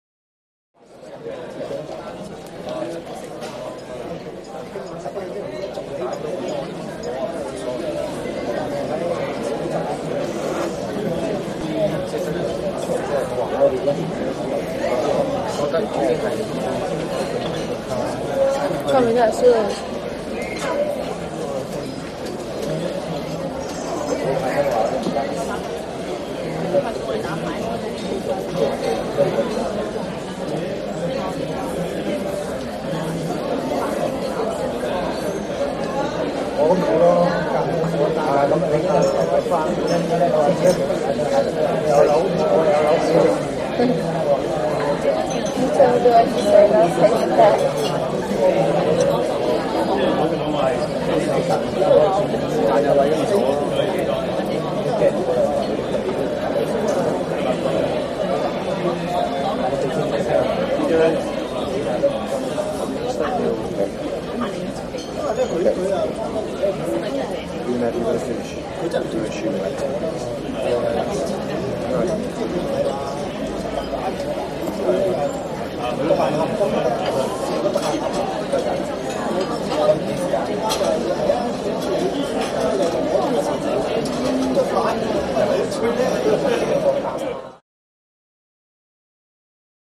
Interior Large Chinese Crowd Chatter.